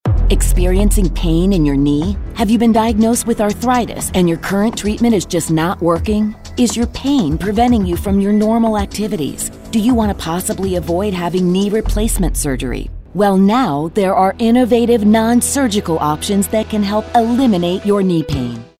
announcer, authoritative, compelling, confident, Gravitas, retail, serious